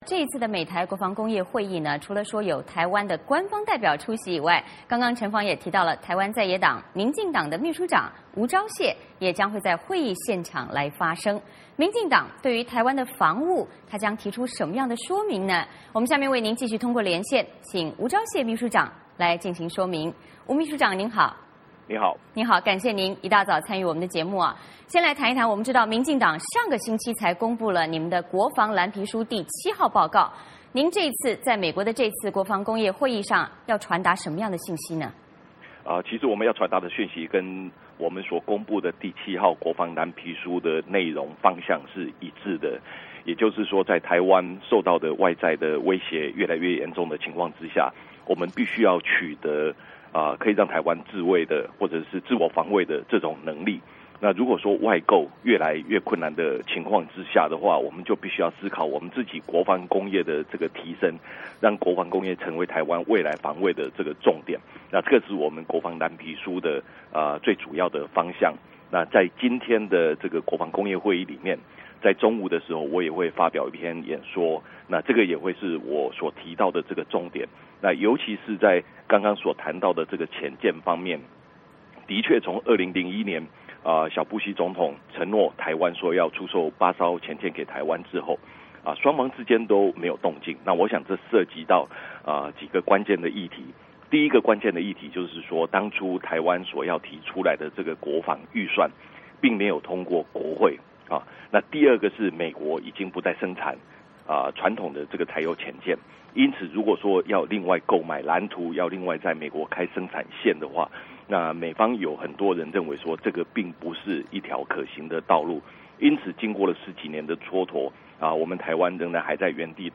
VOA连线：吴钊燮：国防不分蓝绿，民进党推自主国防
这次美台国防会议，除了台湾官方代表出席以外，台湾在野党民进党秘书长吴钊燮也将在会议现场发声，民进党对于台湾防务将提出什么样的说明？下面我们通过连线，请台湾民进党秘书长吴钊燮秘书长来进行说明。